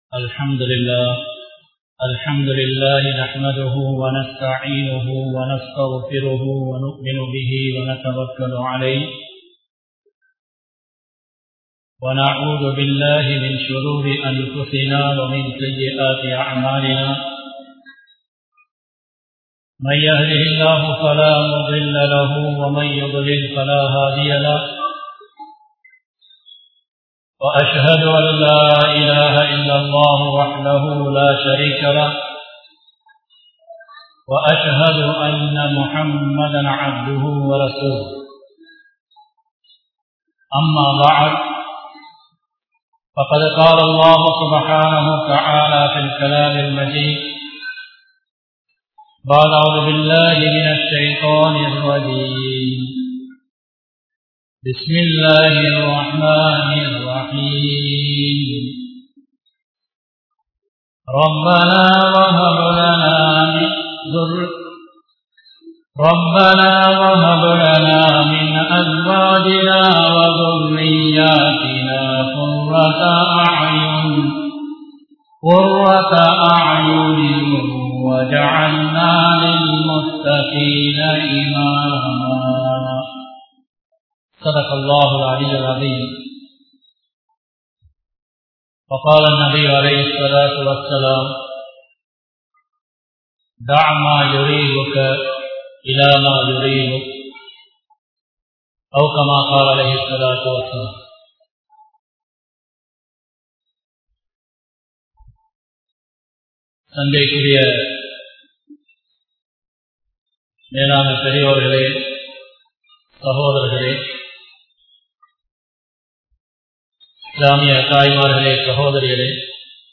Halaal and Haraam(ஹலாலும் ஹறாமும்) | Audio Bayans | All Ceylon Muslim Youth Community | Addalaichenai
Mohideen Grand Jumua Masjith